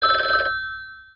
Звук звонка телефона Говорящего Бена